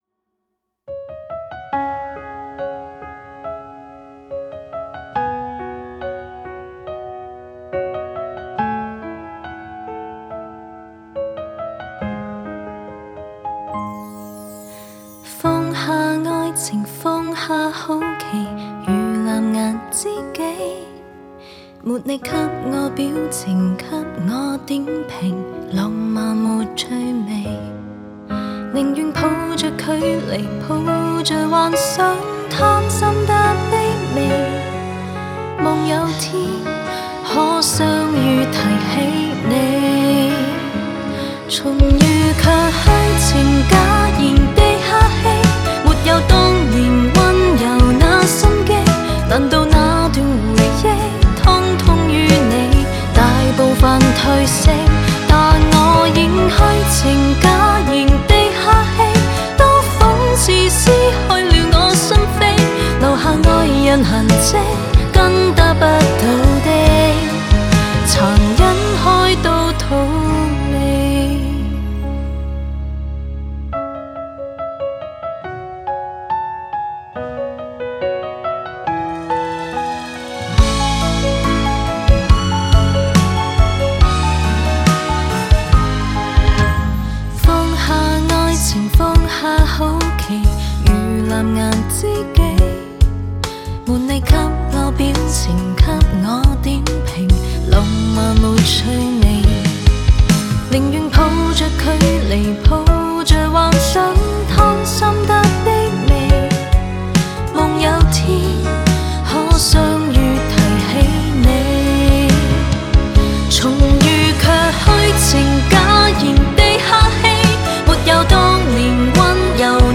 Ps：在线试听为压缩音质节选，体验无损音质请下载完整版
和声
吉他